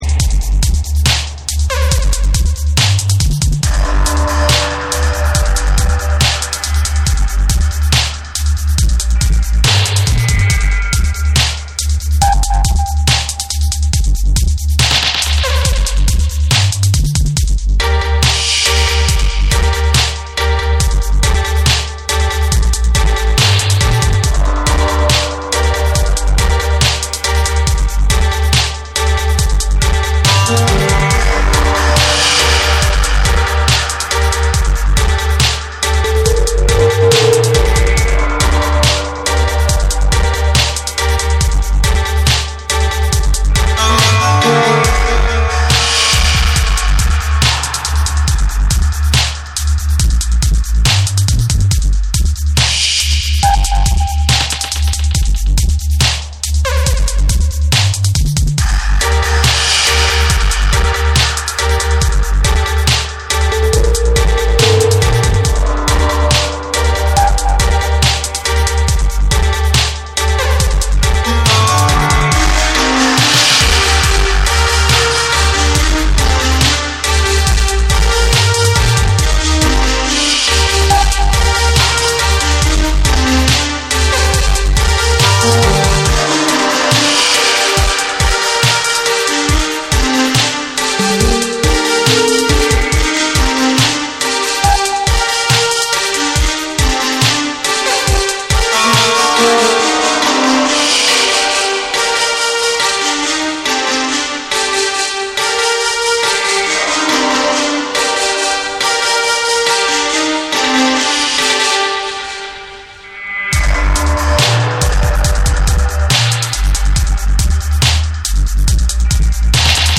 ダブステップ〜サウンドシステム・カルチャーを軸に、重厚なベースと空間処理を駆使した深遠なサウンドスケープを展開。
BREAKBEATS / REGGAE & DUB